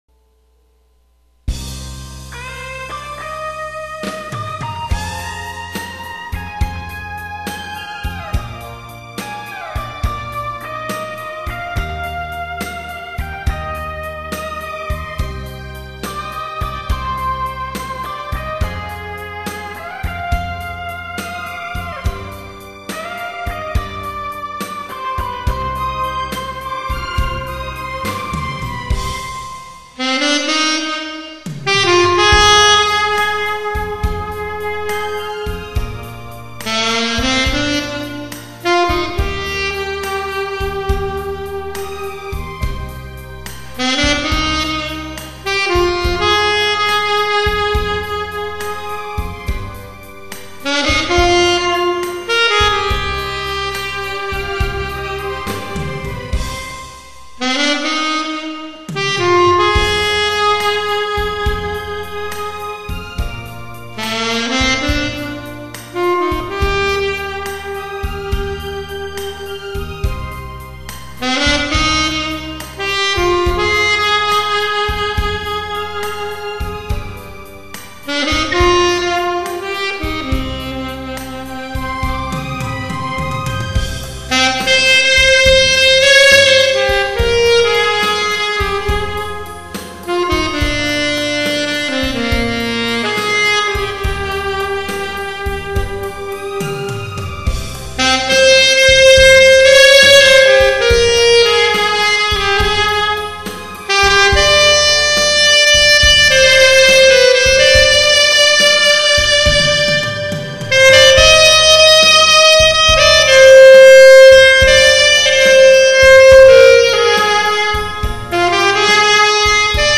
아직 녹음기술이 서툴러 여러모로 미숙합니다